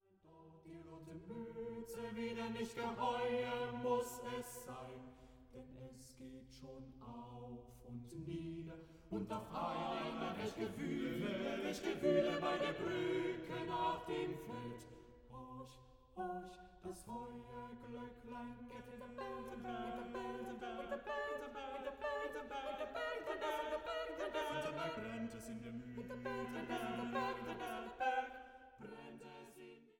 kristallklar aufgenommen im Gewandhaus zu Leipzig